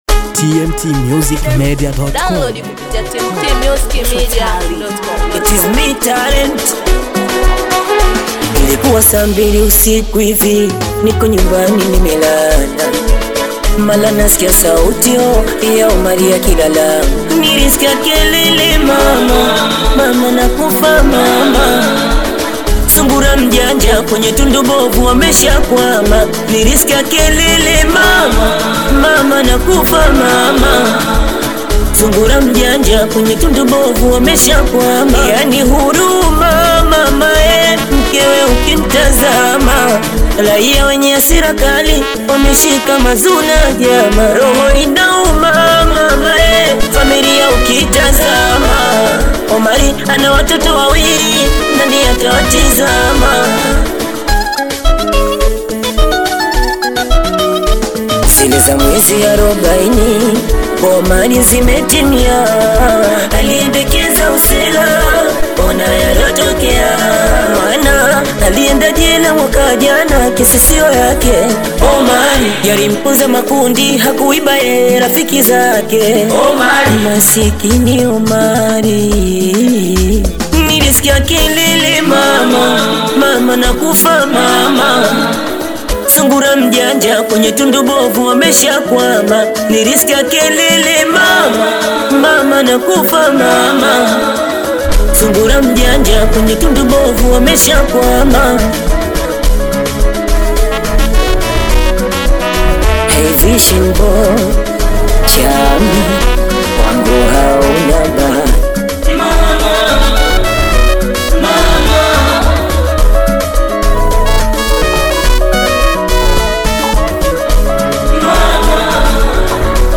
singeli song